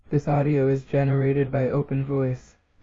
[AI][TTS][OpenVoice]
मैंने एक छोटा सा वॉयस सैंपल रिकॉर्ड किया, नोटबुक फिर से चलाया... और आखिरकार अपना AI-जेनरेटेड ऑडियो मिल गया।
मेरी असली आवाज़ की जगह एक बहुत ही सामान्य TTS वॉयस मिली, शायद मेरी टोन की थोड़ी झलक... लेकिन मेरी पर्सनैलिटी बिल्कुल नहीं।